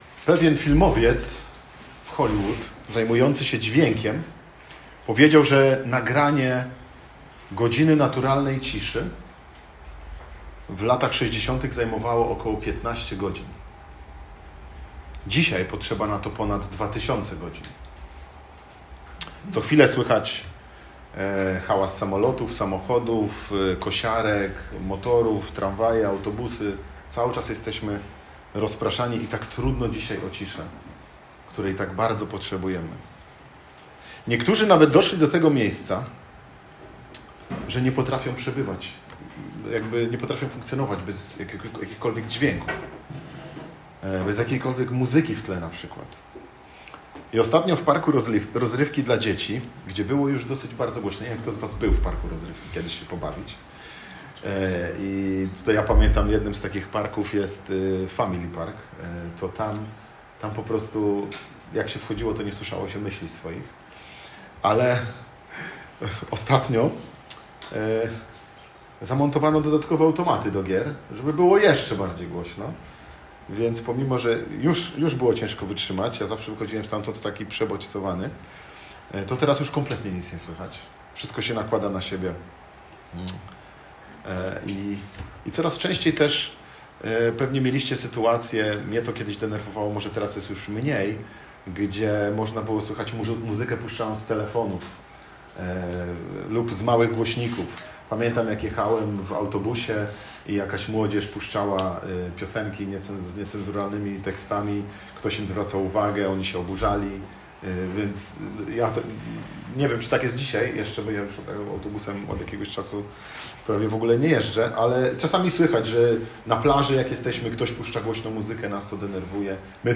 I o tym jest właśnie niniejsze kazanie!